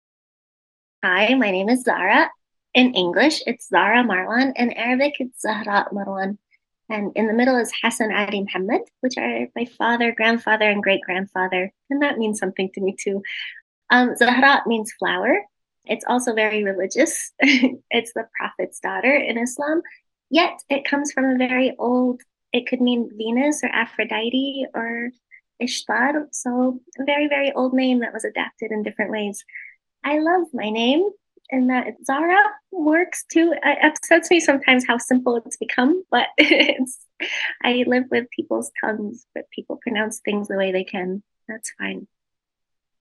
Audio Name Pronunciation
Educator Note: This primary source recording gives insight into a book creator and is not directly tied to a specific book.